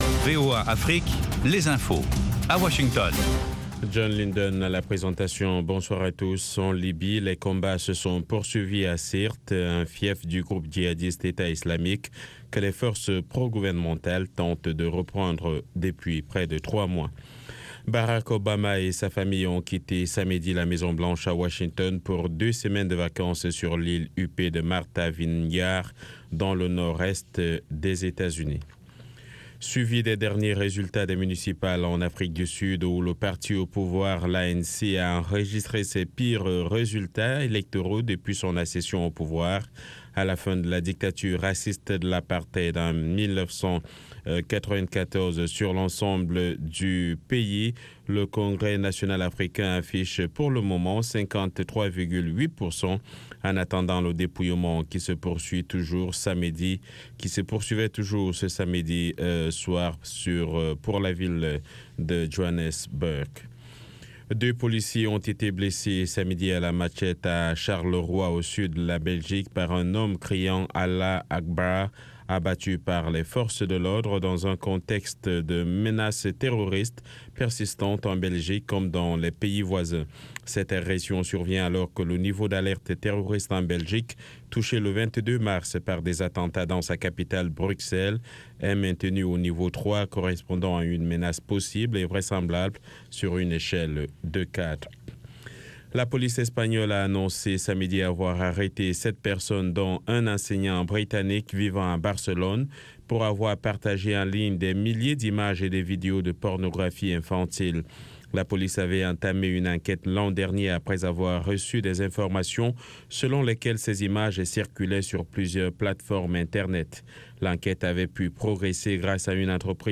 RM Show - R&B et Rock